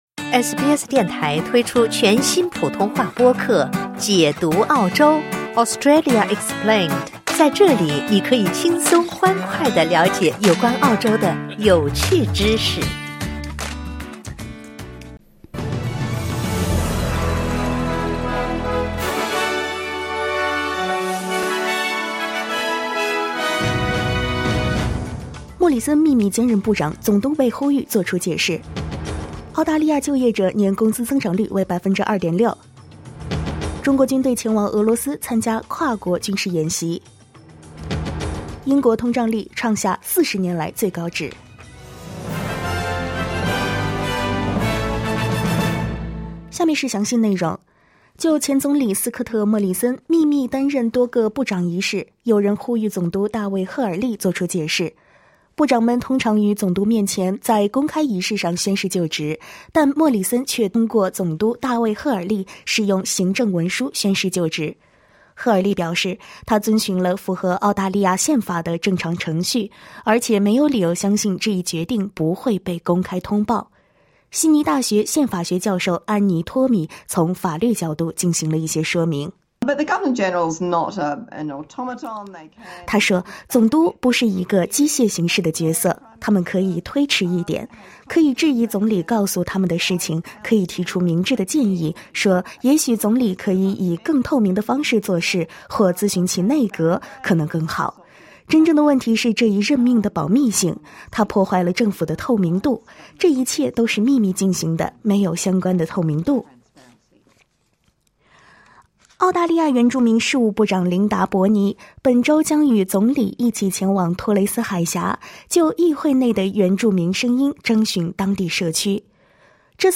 SBS早新闻（8月18日）